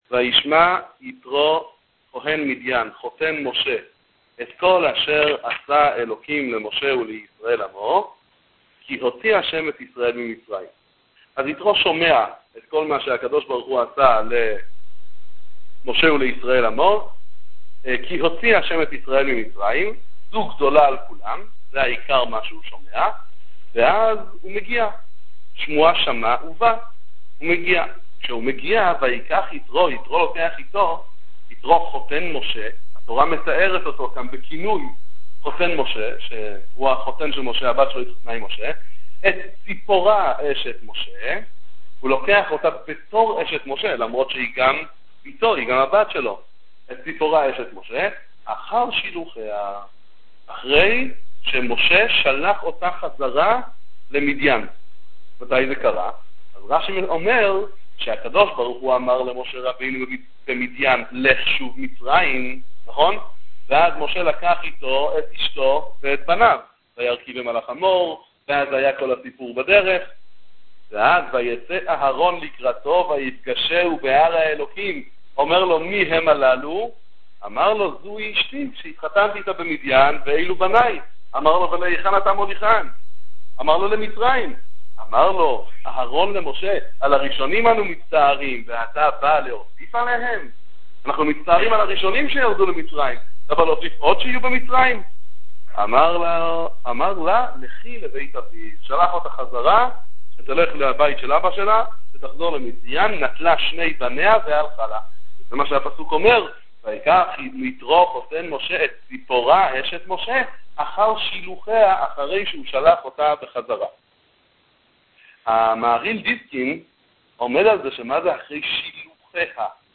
בית המדרש משאת מרדכי רמב"ש א'